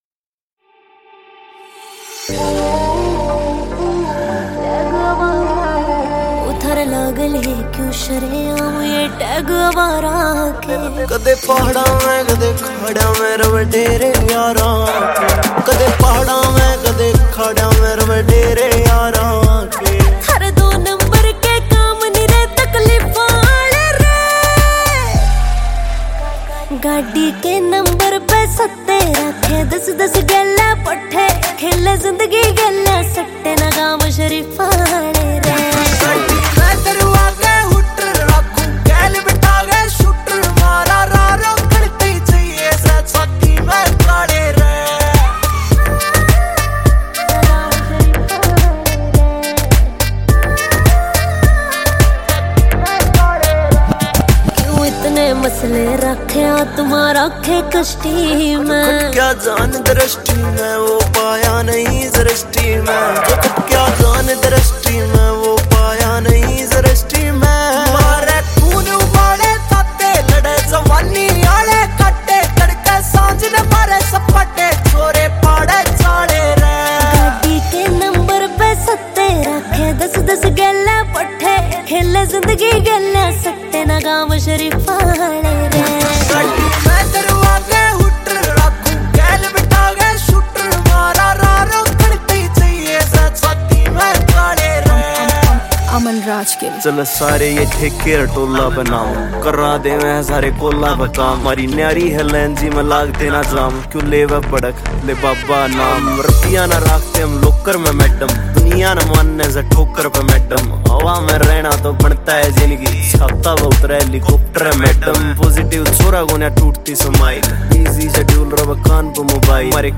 Haryanvi Song